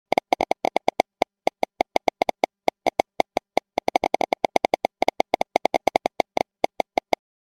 Radioactive Geiger Counter Click Sound Effect
Radioactive Geiger counter click sound effect delivers realistic ticking and clicking for nuclear, sci-fi, or suspense scenes. Perfect for films, games, animations, or videos that need tension, atmosphere, and a sense of radiation detection.
Radioactive-geiger-counter-click-sound-effect.mp3